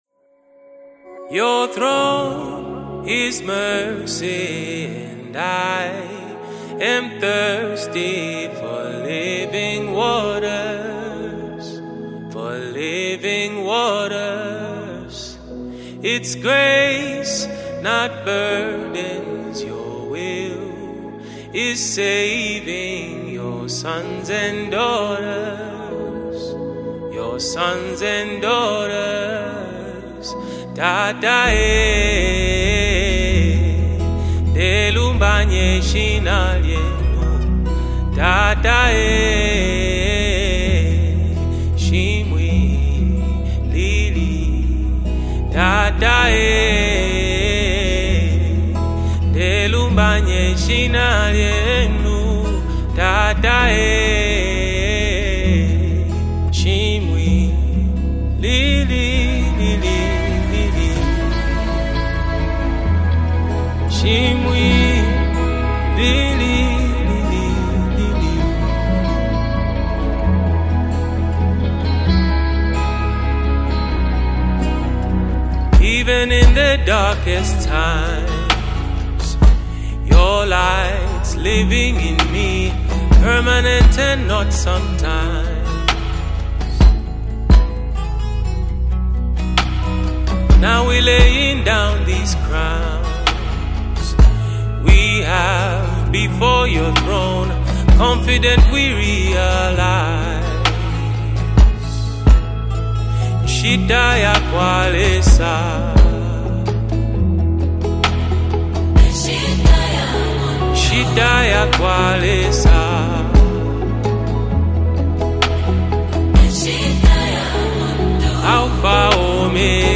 Categories: GospelMusicVideos